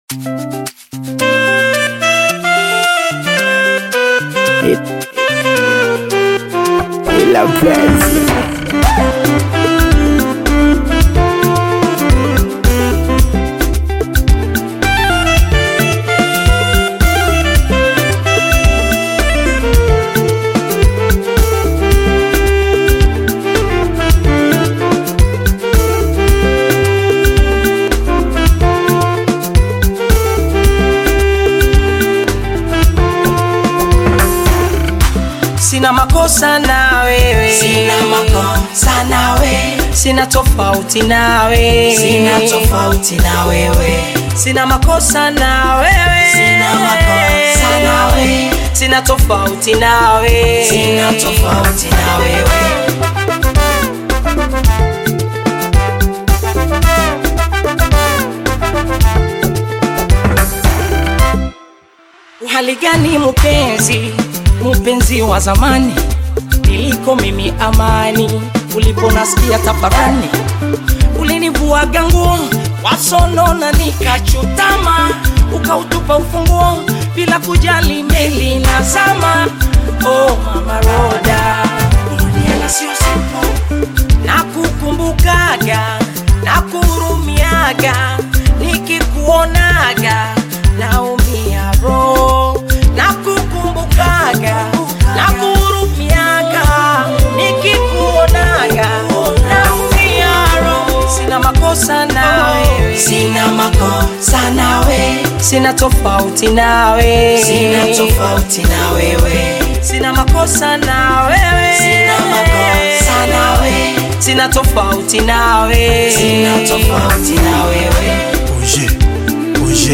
Bongo Flava
Tanzanian Bongo Flava artist and rapper